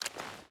Water Walk 2.ogg